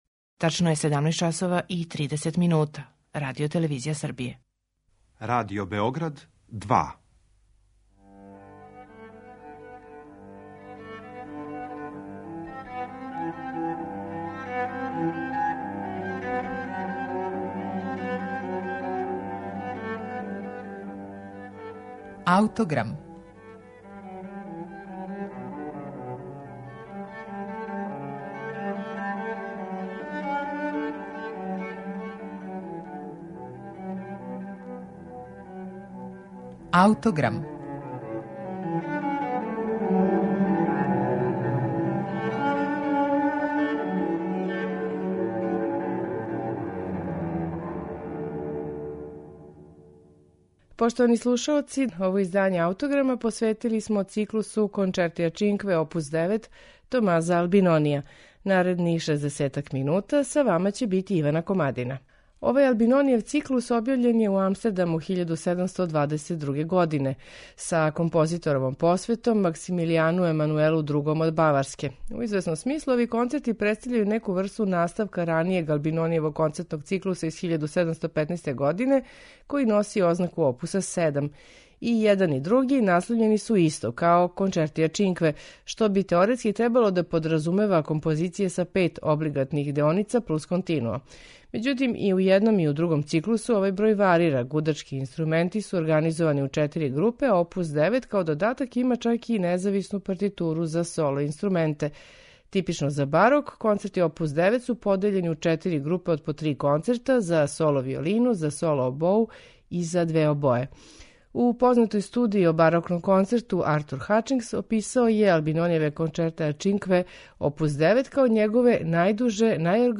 на оригиналним барокним инструментима
Ендрју Манце, виолина
обоа
харпсикорд. За камерним оругљама и диригентским пултом је Кристофер Хогвуд.